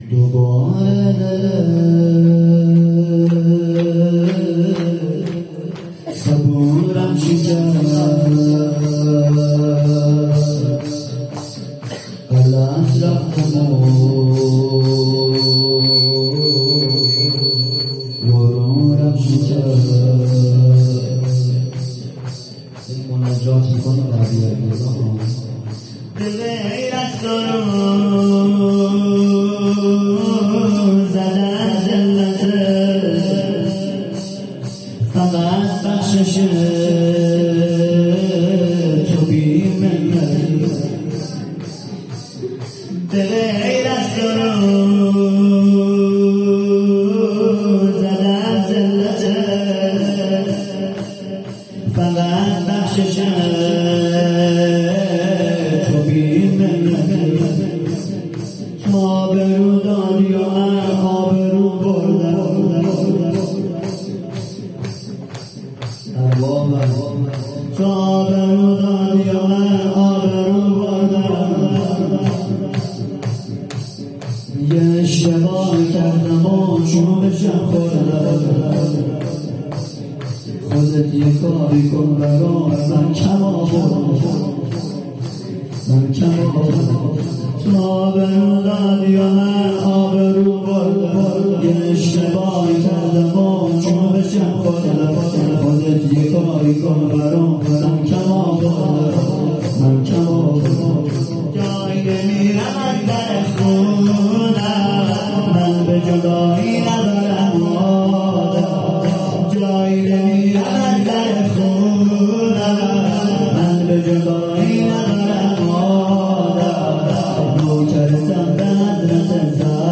جلسه هفتگی/4آبان1400/ 19ربیع الاول1443
شور